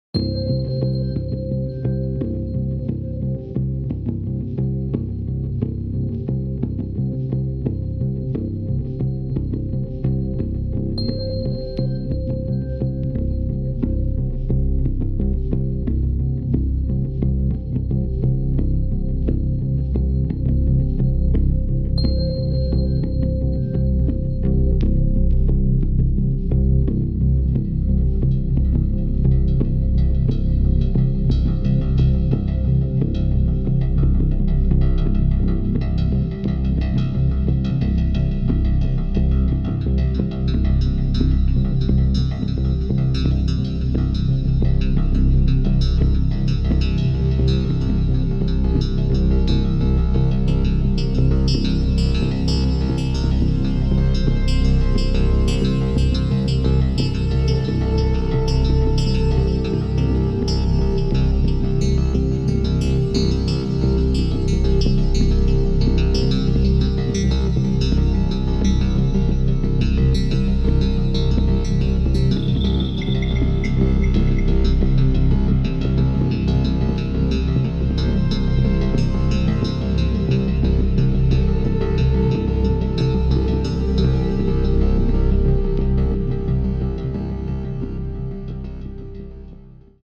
μια μουσική κομμάτι διαλογισμού